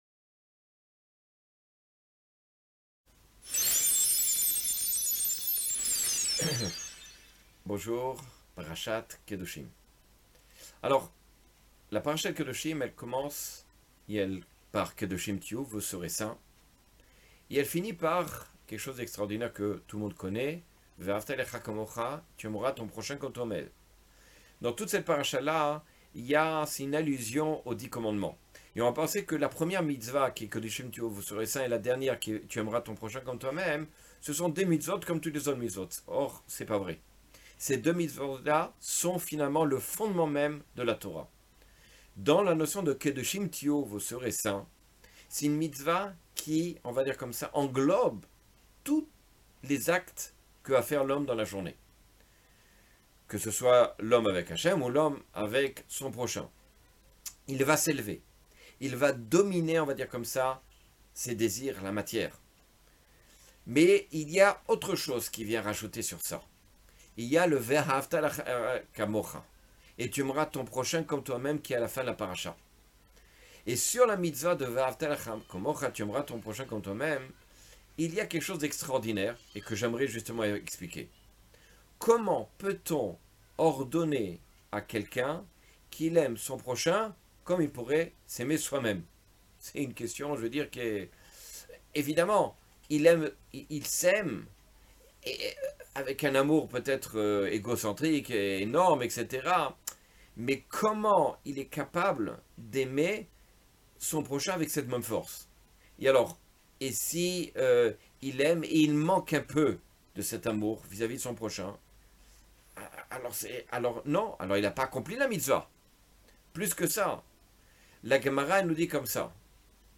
Cours sur l'éducation à travers la parasha Kedoshim.